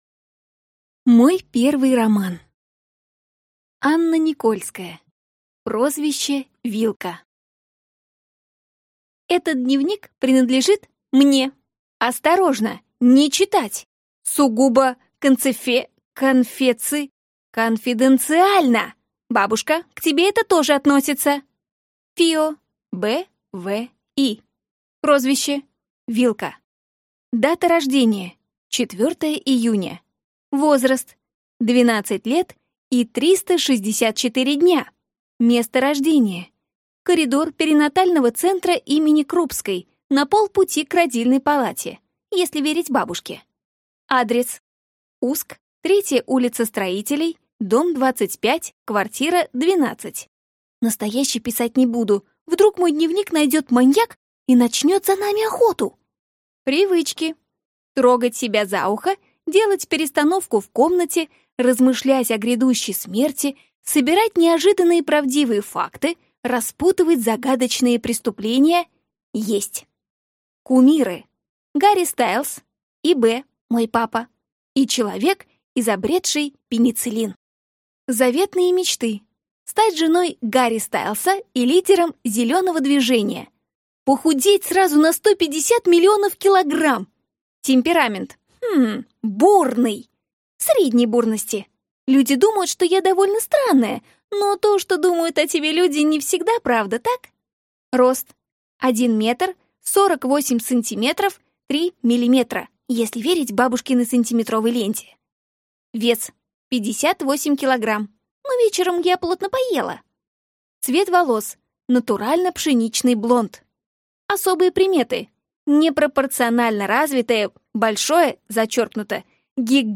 Аудиокнига Прозвище: Вилка | Библиотека аудиокниг